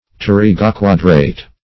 Search Result for " pterygoquadrate" : The Collaborative International Dictionary of English v.0.48: Pterygoquadrate \Pter`y*go*quad"rate\, a. [Pterygoid + quadrate.] (Anat.) Of, pertaining to, or representing the pterygoid and quadrate bones or cartilages.
pterygoquadrate.mp3